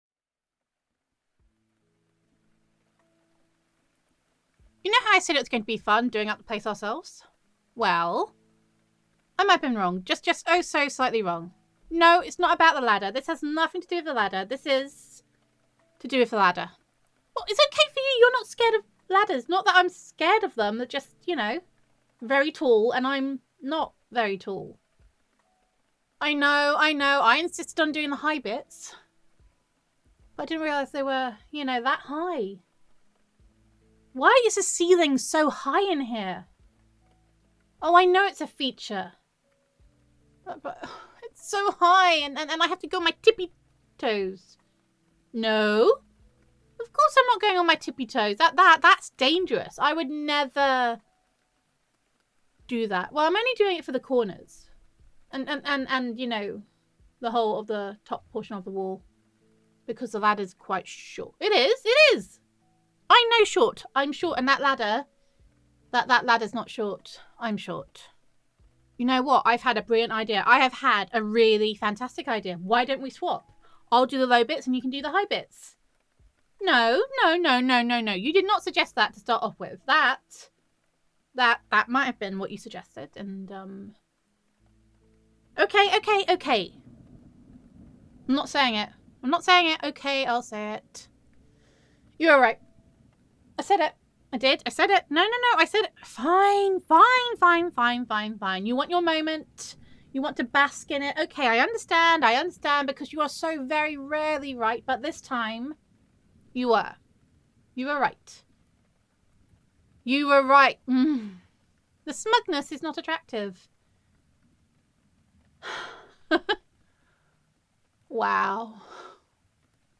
[F4A] Home Is Where the Heart Is [Girlfriend Roleplay][DIY][You Are a Shitty Painter][Adoration][Sweetheart the Plumber][Thank You YouTube][Heartfelt][Domestic Bliss][Gratitude][Gender Neutral][Doing up a House Together Is All About Making a Home Together]